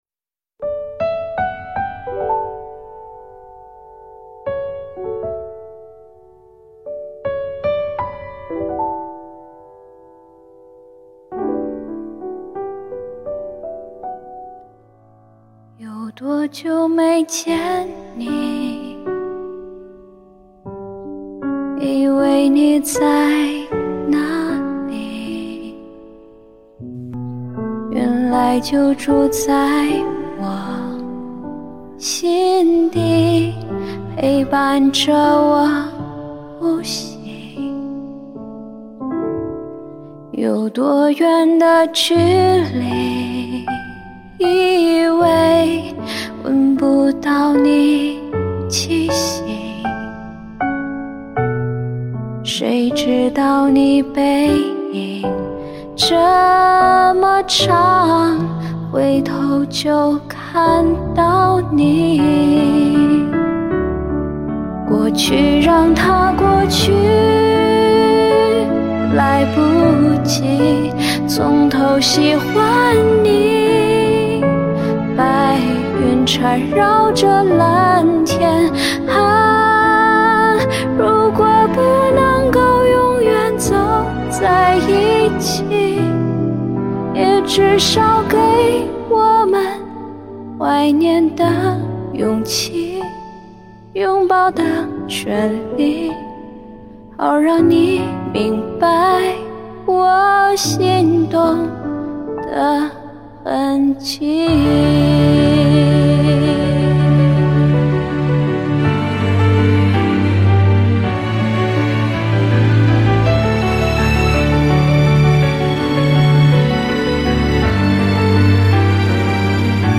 温润的嗓音，饱含岁月的沉淀。没有华丽的技巧炫技，却将每个音符都注入了沉甸甸的情感重量。